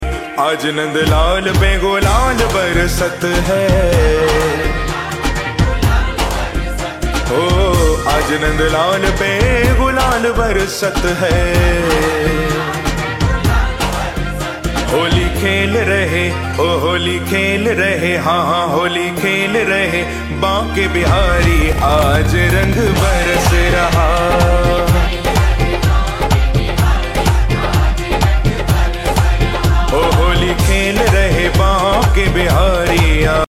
Delving into the Spiritual Melody